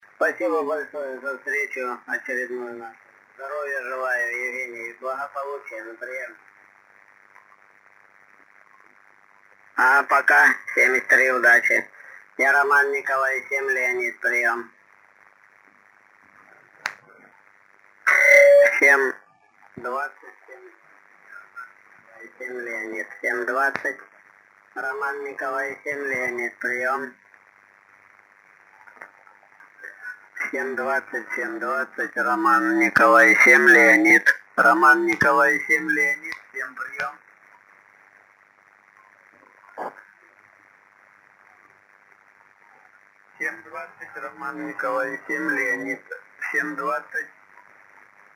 Запись велась: в CW 200Hz, в SSB 2,4kHz.
Продолжаю выкладывать записи работы приемника (tr)uSDX-a. Запись делалась во время проведения IARU HF Championship.
SSB: